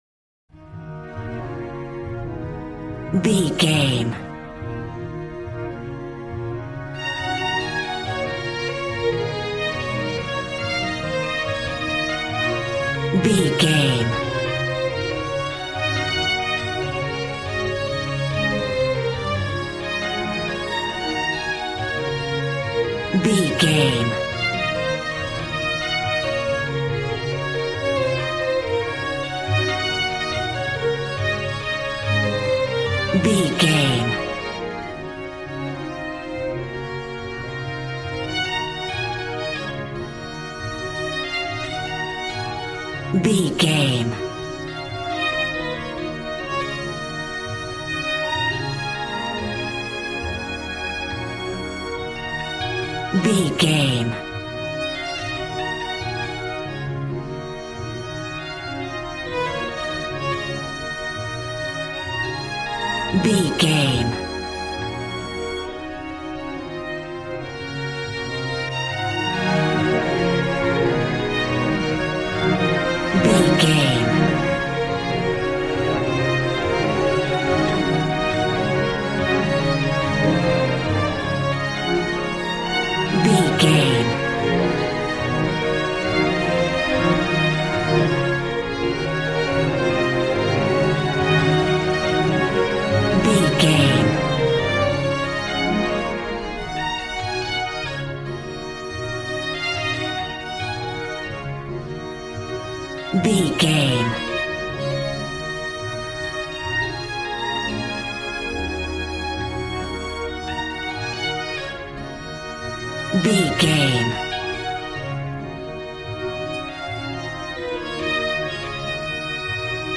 Aeolian/Minor
B♭
joyful
conga
80s